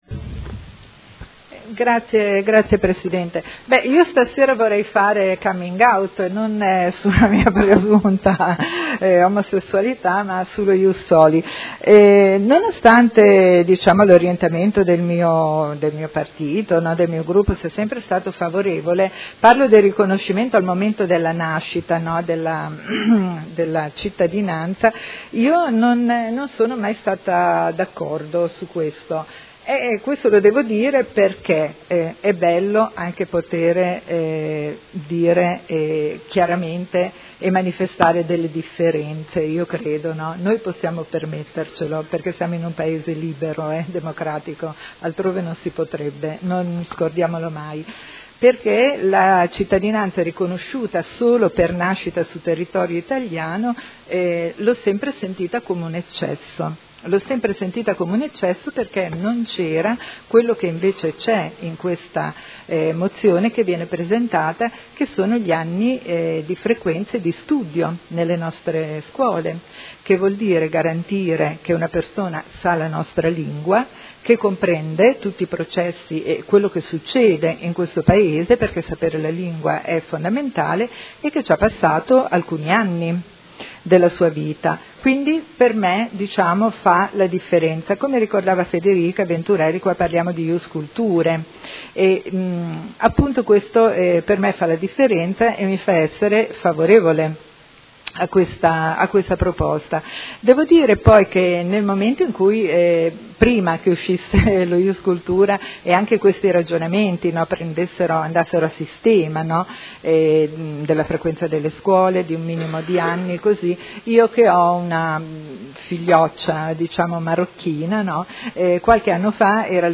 Paola Aime — Sito Audio Consiglio Comunale